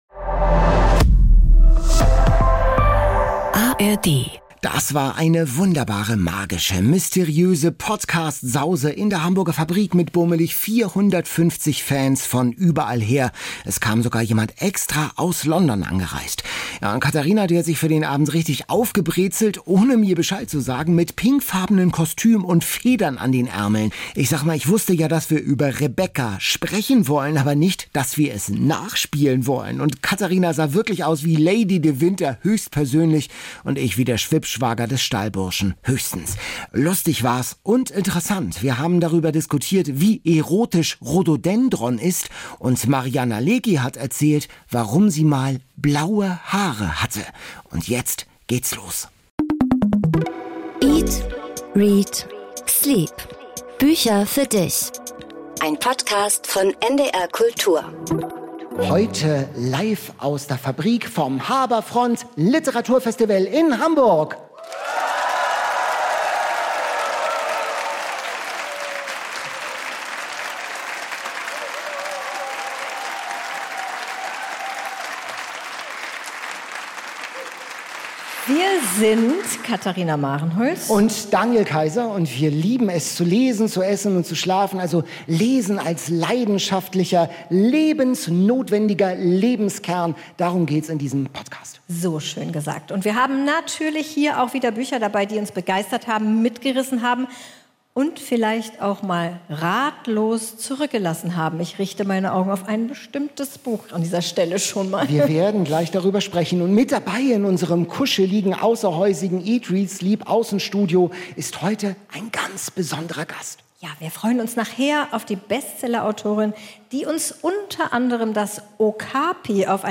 Federblazer, Toaster auf der Bühne und jede Menge Bücher – der Podcast diesmal live vom Harbourfront Literaturfestival.